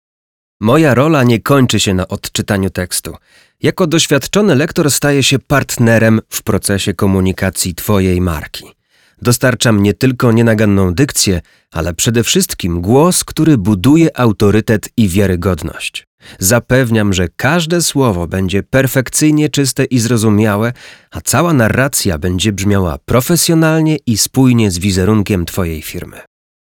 Narracje lektorskie do szkoleń, onboardingów, szkolenia, BPH, prezentacji